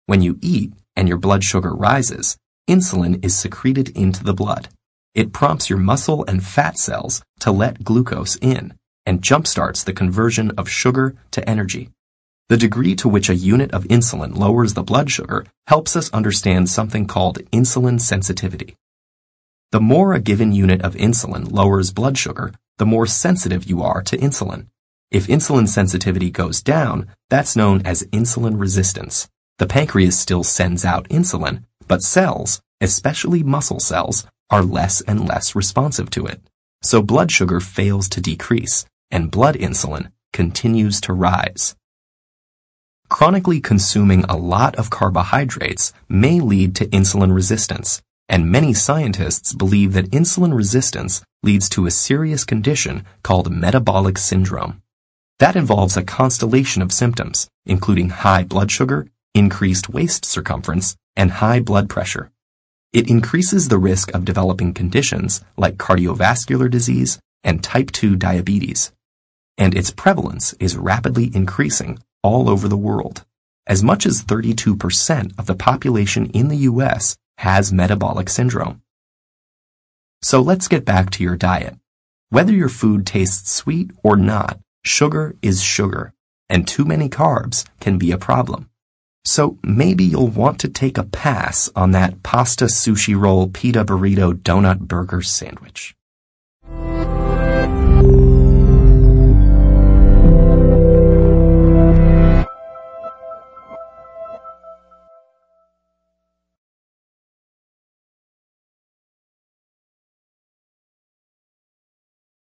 TED演讲:碳水化合物是怎么作用于你的健康的(3) 听力文件下载—在线英语听力室